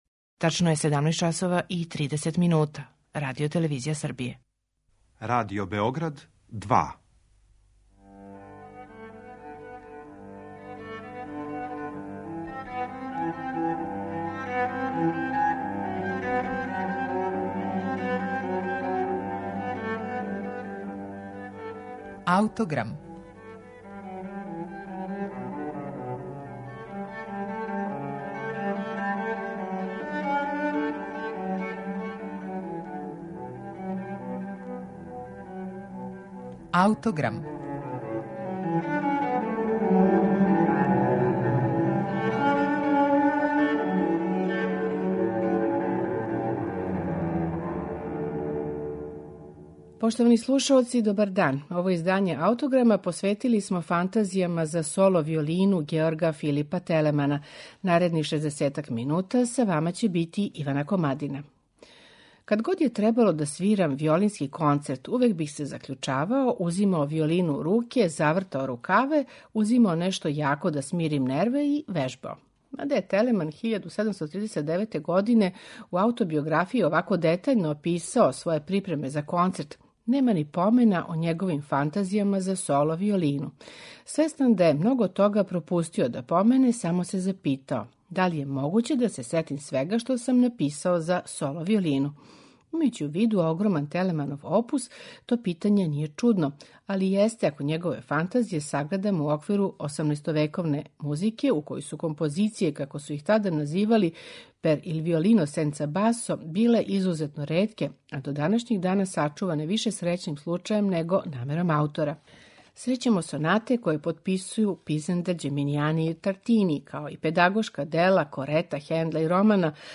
Фантазије за виолину
Међутим, виолинске фантазије гледају у оба смера: у њима срећемо фуге, али и тада модерне плесне форме. Аутор овде истражује и могућности виолине да истовремено свира и мелодијску линију и њену пратњу, као и да подржи неколико контрапунктских линија.
У данашњем Аутограм у ове Телеманове фантазије слушамо у интерпретацији виолинисте Ендруа Манцеа.